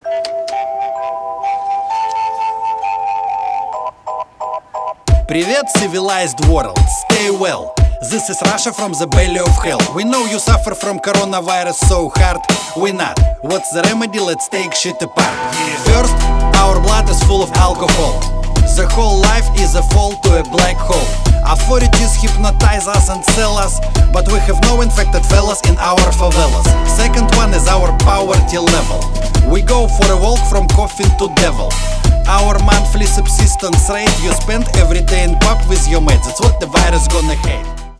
Рэп и Хип Хоп # Юмор